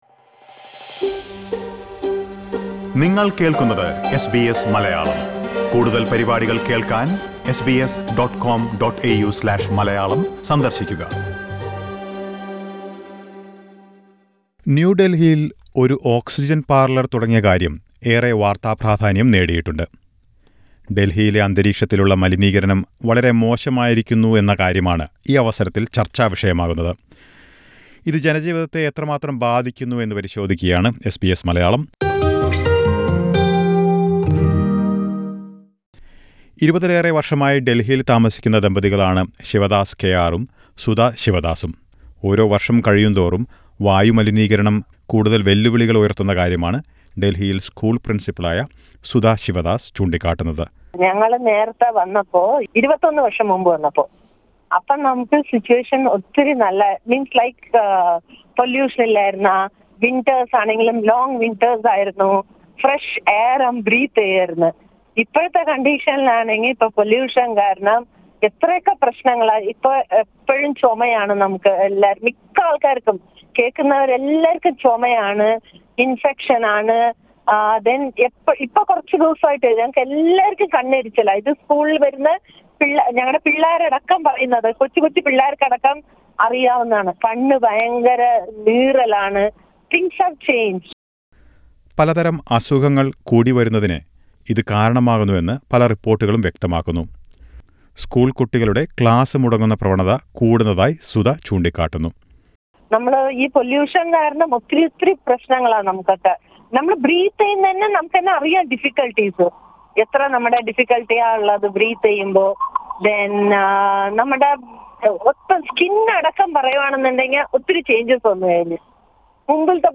ഡൽഹിയിൽ ഒരു കടയിൽ ഓക്സിജൻ വിൽക്കാൻ തുടങ്ങിയത് ചർച്ചാവിഷയമായിരുന്നു. അതിമലിനമായ അന്തരീക്ഷത്തെ തുടർന്ന് ഡൽഹിയിൽ പ്രതിസന്ധിയുടെ ഗൗരവമാണ് ഇത് ചർച്ചാ വിഷയമായതിന്റെ കാരണമായി കണക്കാക്കുന്നത്. ഡൽഹിയിൽ സ്ഥിരതാമസമാക്കിയിരിക്കുന്ന ചില മലയാളികളോട് ഇതേക്കുറിച്ച് സംസാരിക്കുകയാണ് എസ് ബി എസ് മലയാളം.